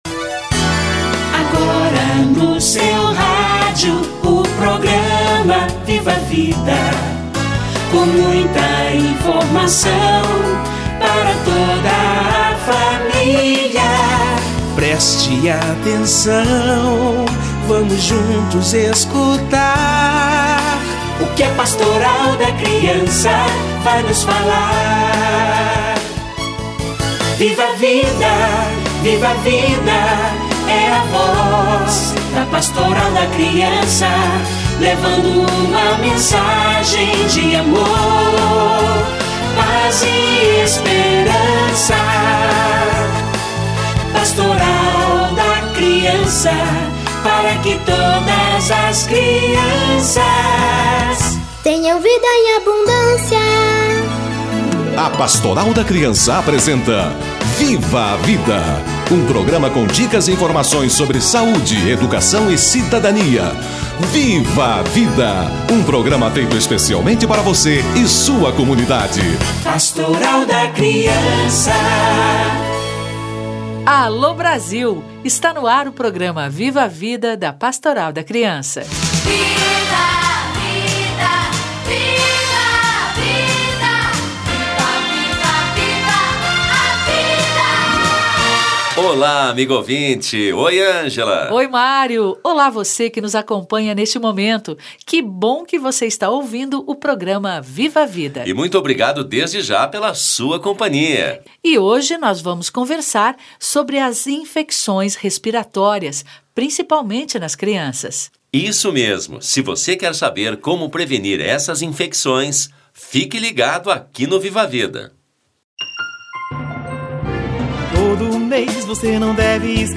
Infecções respiratórias - Entrevista